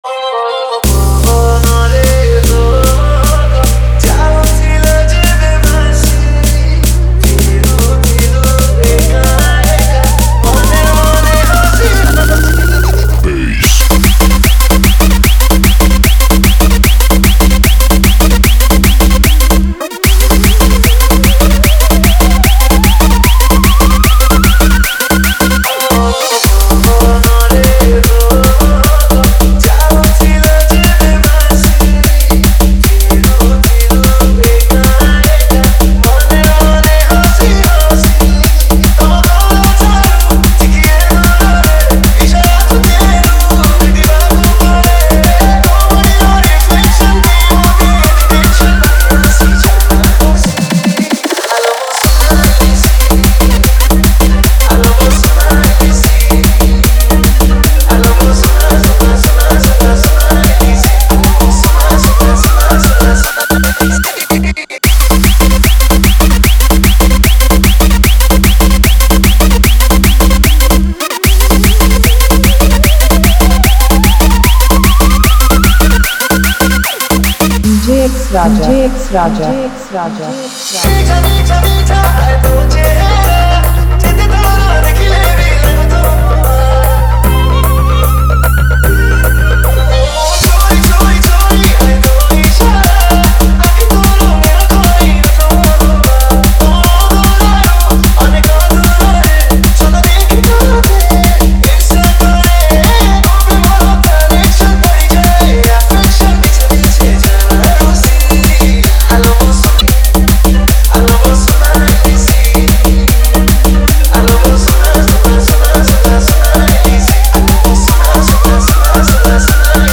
Trance Mix